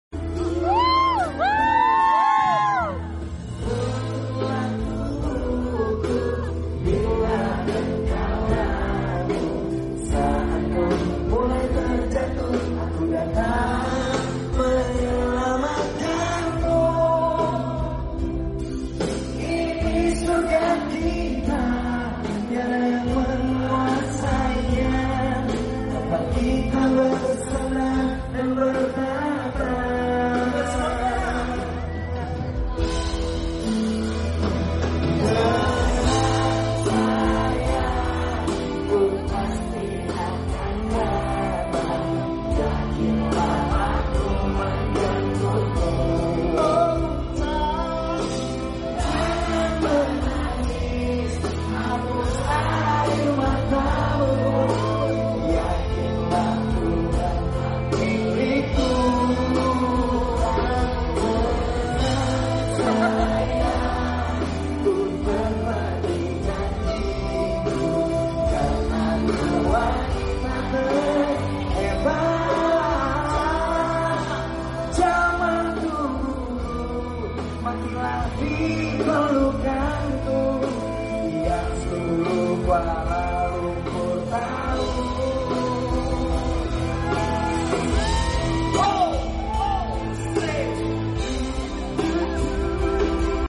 Live In Kuala Lumpur 2024